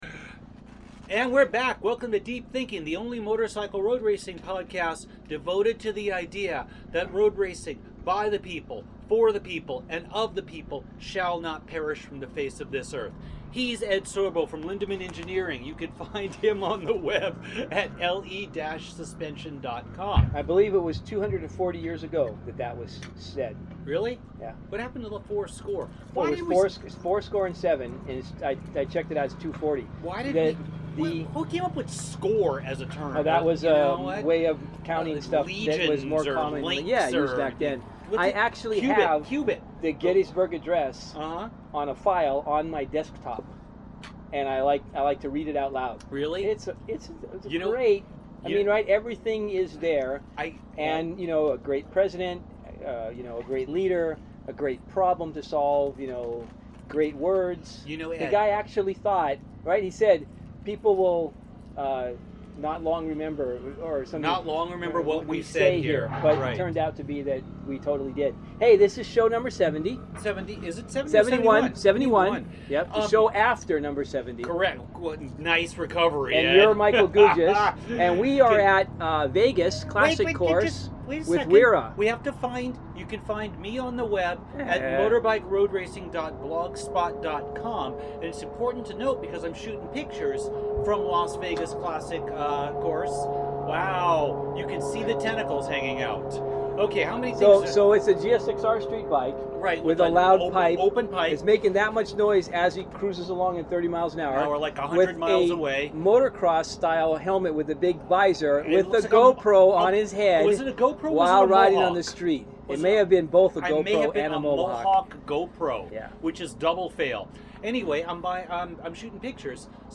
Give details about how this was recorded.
record in the pits at the Las Vegas Classic Course. A discussion ensues about a 1998 Honda Interceptor.